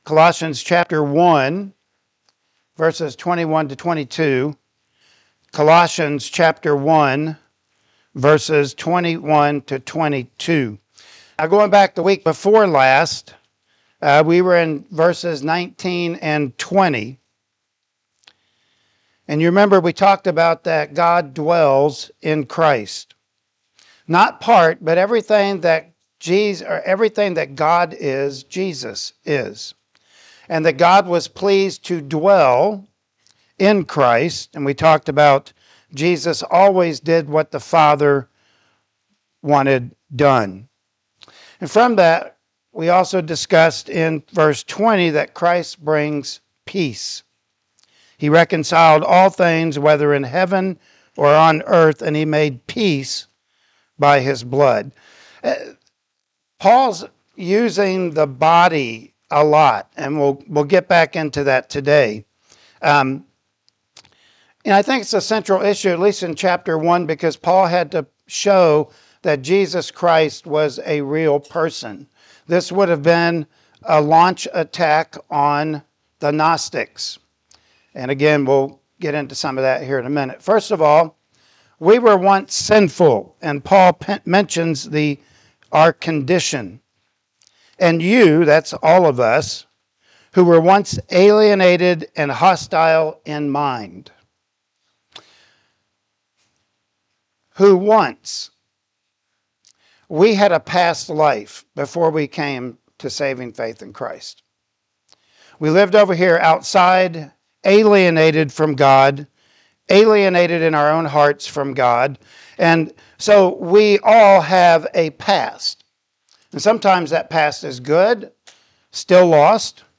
Colossians 1:21-22 Service Type: Sunday Morning Worship Service Topics: Inward Transformation « In Christ Alone